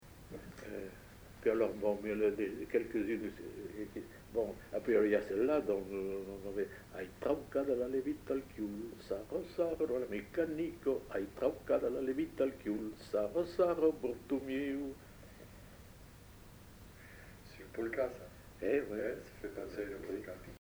Lieu : Saint-Sauveur
Genre : forme brève
Effectif : 1
Type de voix : voix d'homme
Production du son : chanté
Classification : formulette enfantine